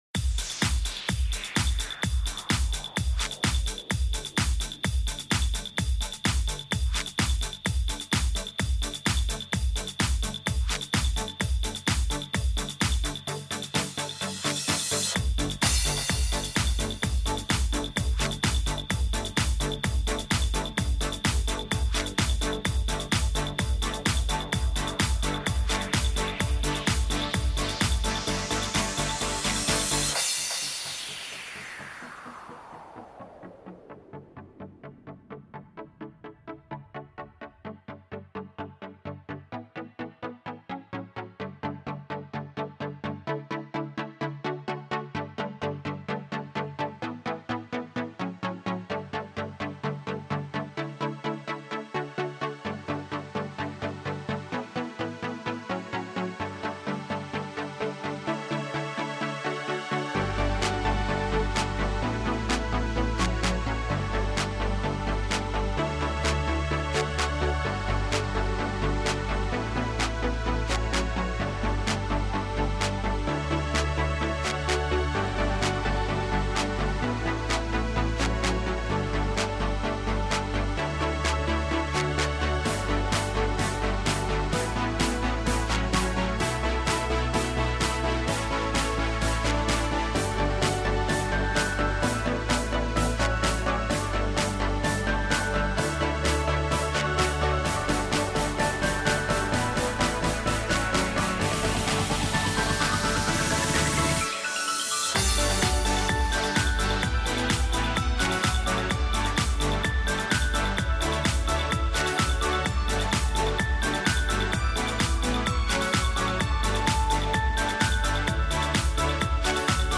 House track I made.
genre:house